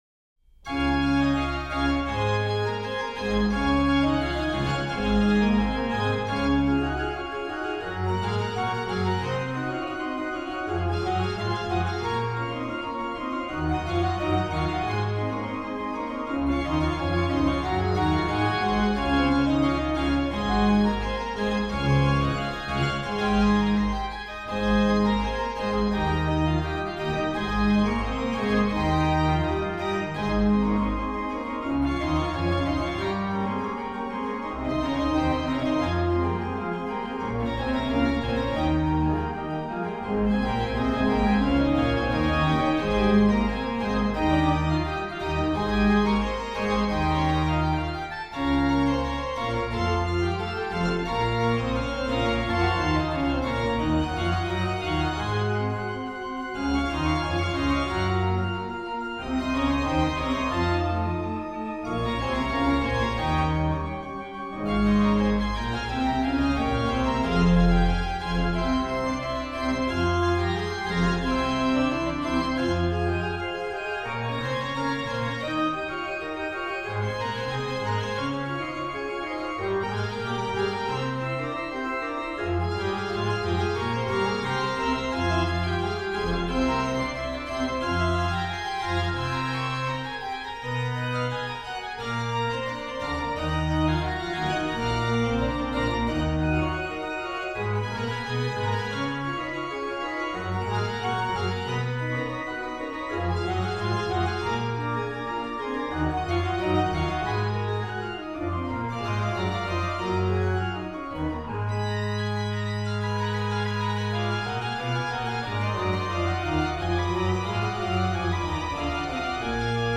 Registration   POS: Lged8, Pr4, Oct2, Mix
PED: Sub16, Oct8, Oct4, Tr8
m. 3: MAN: Bor8, Oct4, Oct2